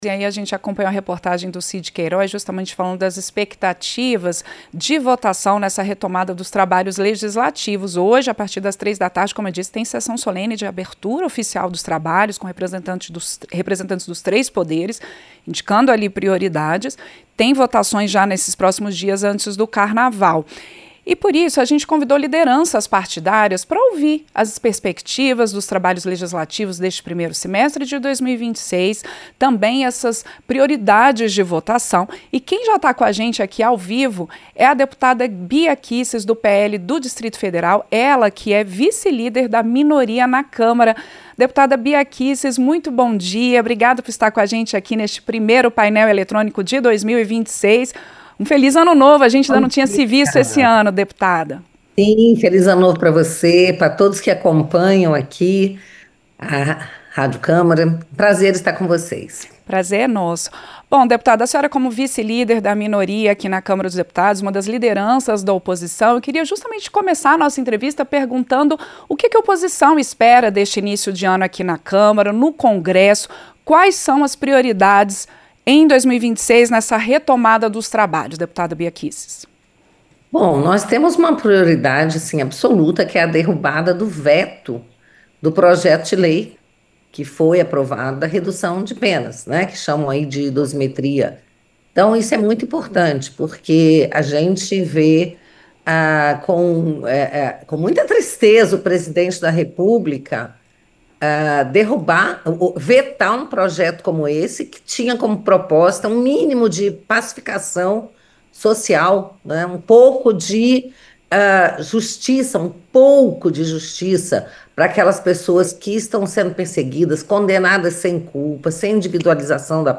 Vice-líder da minoria na Câmara dos Deputados, a deputada Bia Kicis (PL-DF) apontou a derrubada do veto ao chamado PL da dosimetria e a instalação de uma CPI sobre as fraudes no Banco Master como prioridades da oposição nesta retomada de trabalhos legislativos, a partir desta segunda-feira (2). A parlamentar falou ao vivo ao Painel Eletrônico.
Entrevista - Dep. Bia Kicis (PL/DF)